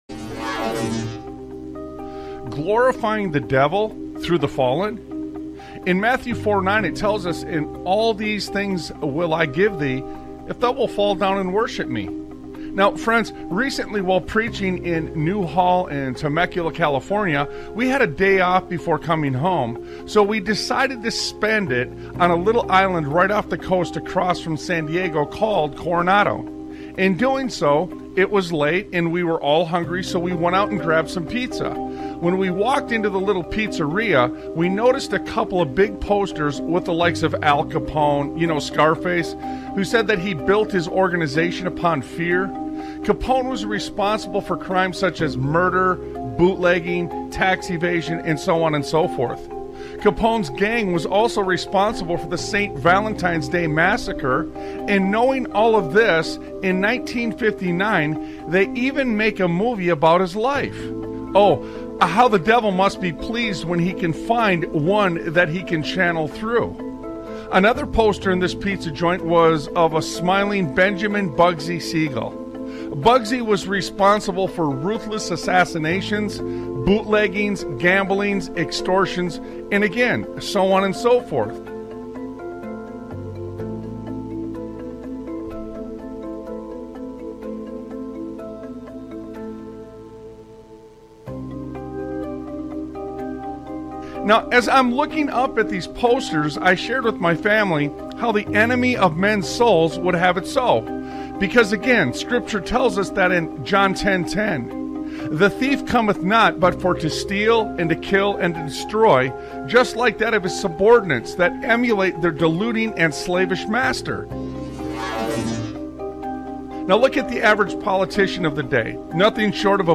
Talk Show Episode, Audio Podcast, Sons of Liberty Radio and As Much As They Want This To Go Away, It Isn't! on , show guests , about As Much As They Want This To Go Away,It Isn't, categorized as Education,History,Military,News,Politics & Government,Religion,Christianity,Society and Culture,Theory & Conspiracy